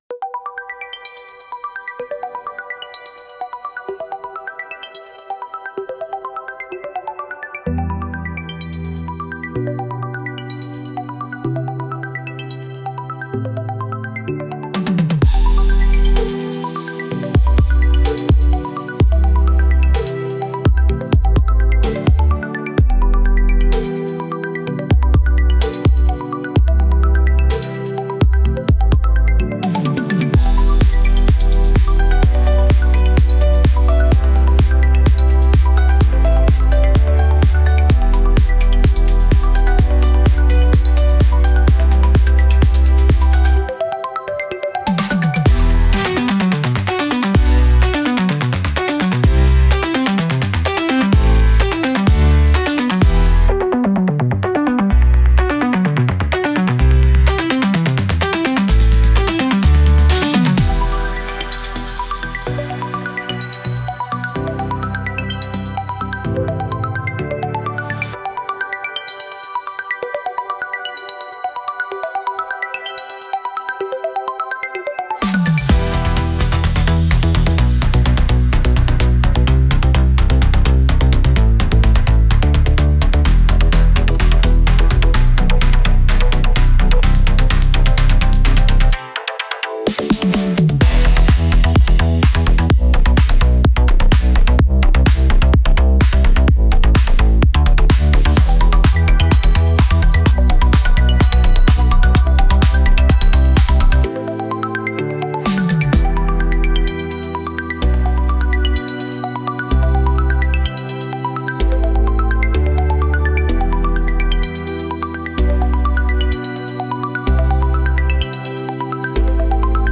Genre: EDM Mood: Energy Editor's Choice